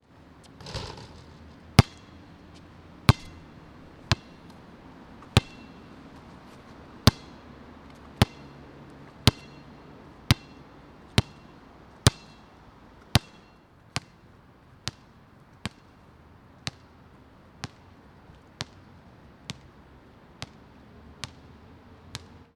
На этой странице собраны звуки мяча в разных ситуациях: удары, отскоки, броски.
Звук удара мяча о землю